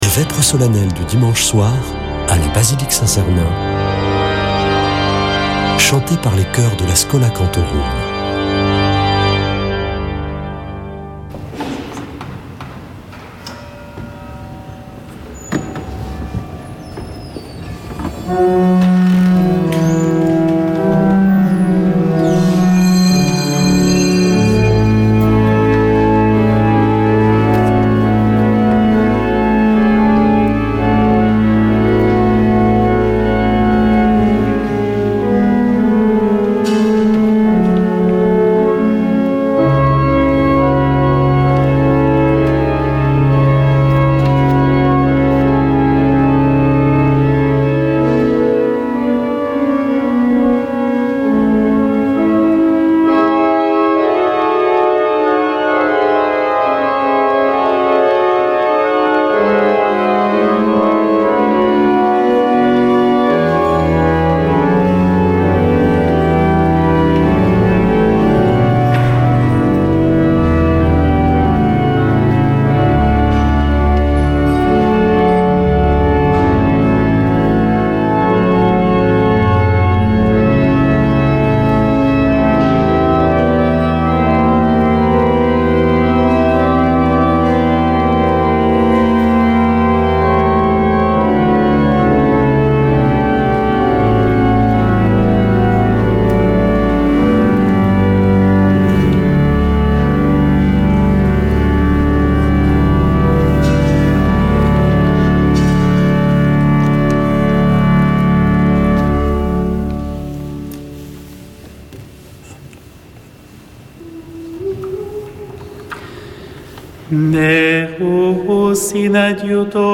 Vêpres de Saint Sernin du 16 avr.
Une émission présentée par Schola Saint Sernin Chanteurs